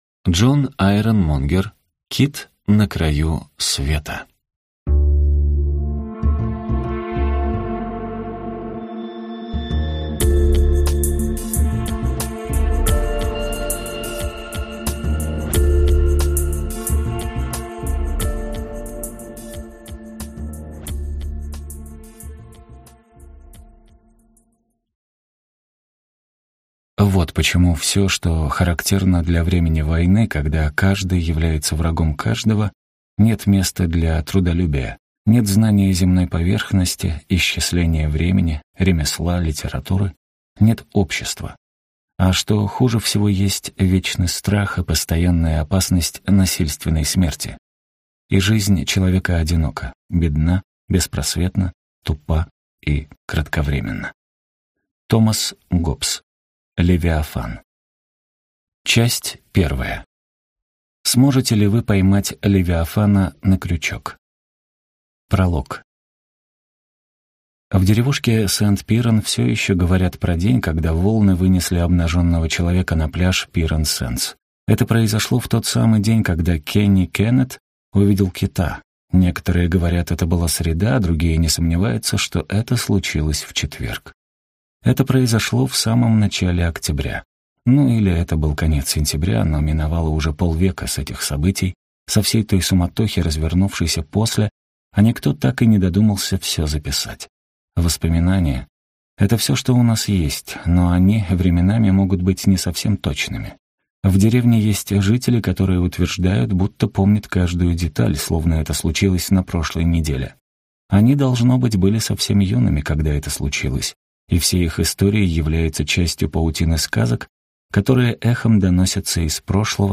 Аудиокнига Кит на краю света | Библиотека аудиокниг